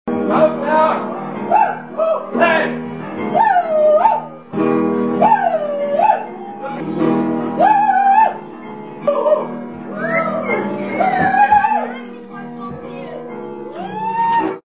live 2